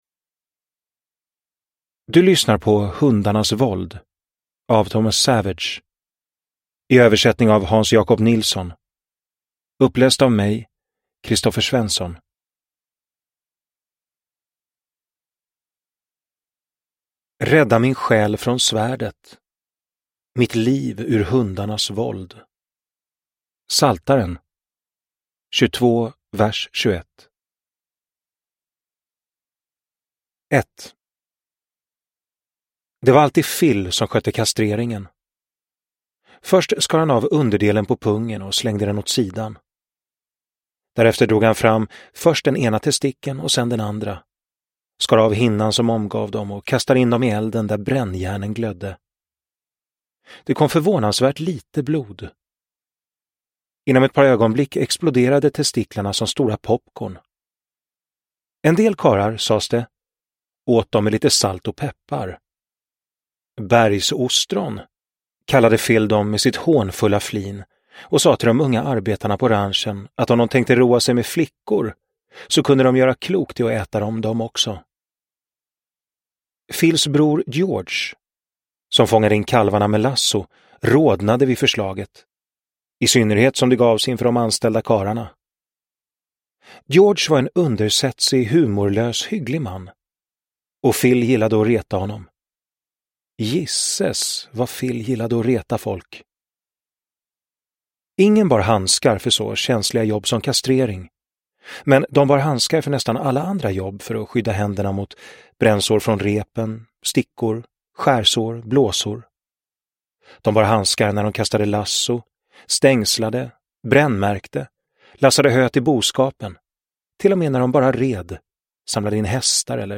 Hundarnas våld – Ljudbok – Laddas ner